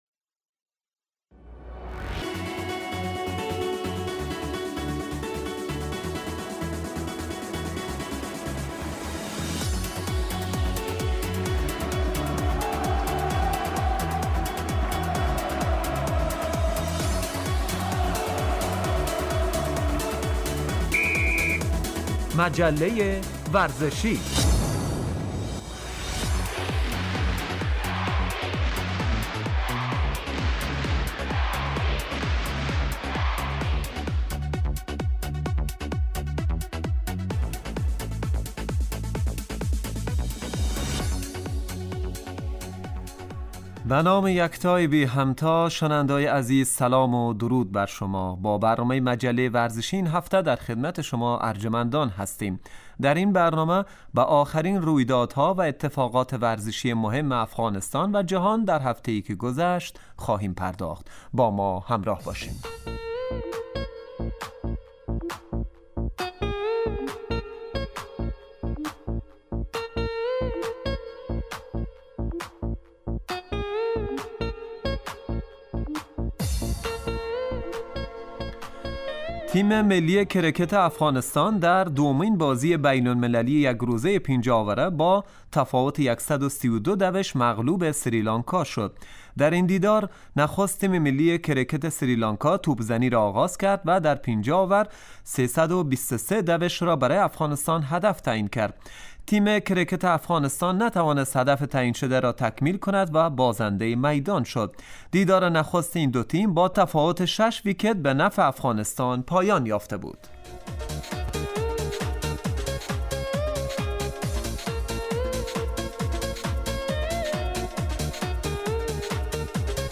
آخرين اخبار و رويدادهاي ورزشي افغانستان و جهان در هفته اي که گذشت به همراه گزارش و مصاحبه وبخش ورزش وسلامتي